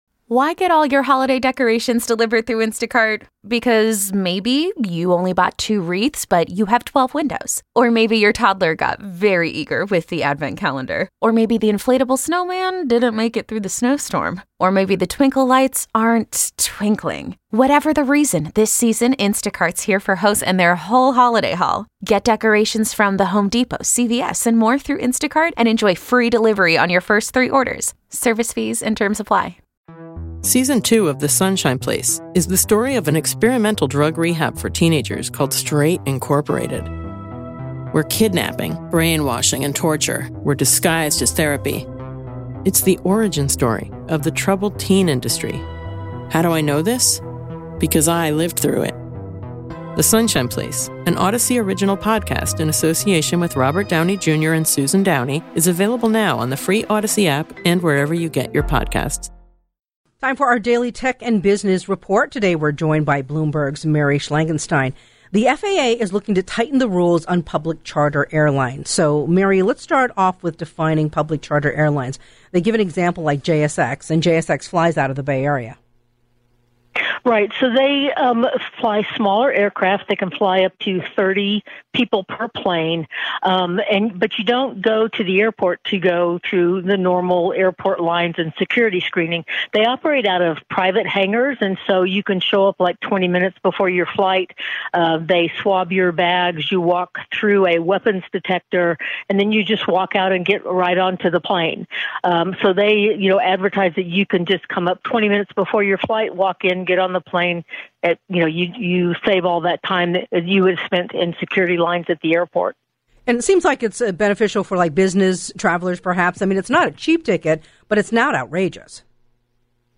This is the KCBS Radio Tech and Business Report.